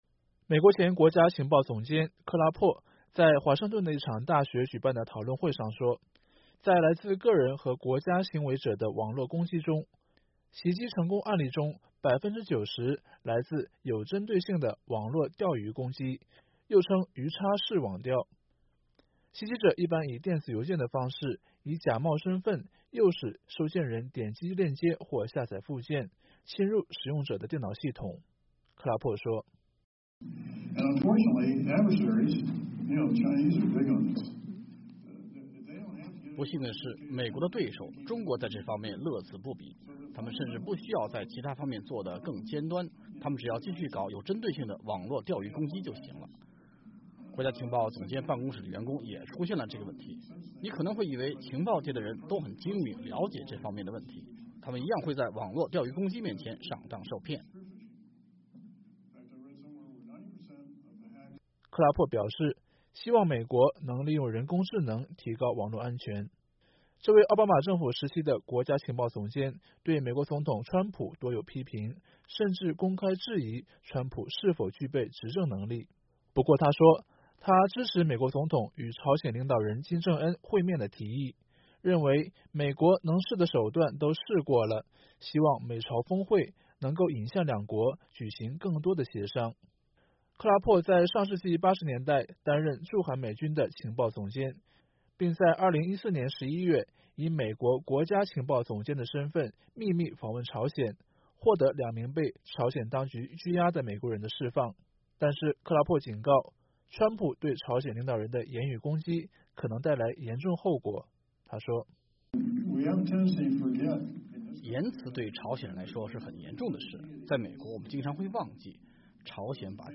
美国前国家情报总监詹姆斯·克拉珀（James Clapper）星期一（4月2日）在华盛顿的一场讨论会上说，中国擅长发动有针对性的网络钓鱼攻击，即使是美国的情报人员也常常“中计”。